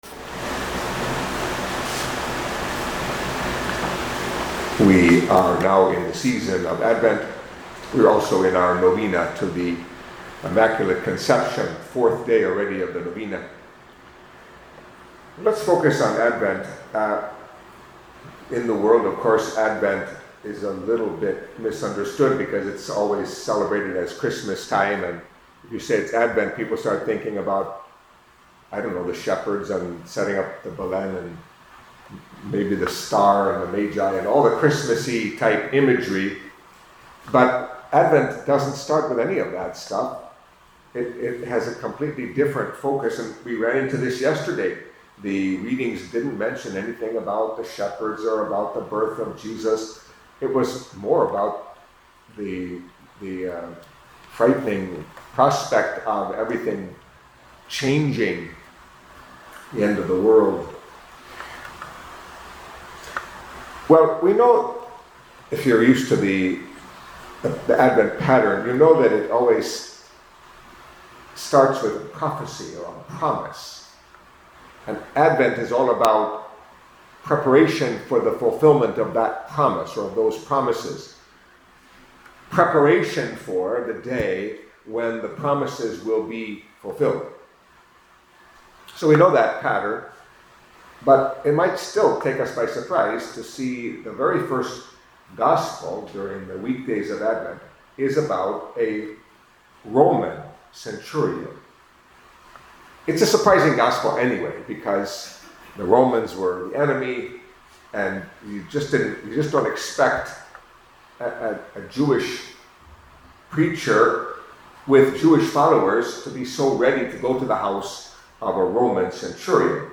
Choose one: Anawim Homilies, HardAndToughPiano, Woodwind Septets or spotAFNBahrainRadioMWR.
Anawim Homilies